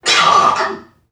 NPC_Creatures_Vocalisations_Robothead [1].wav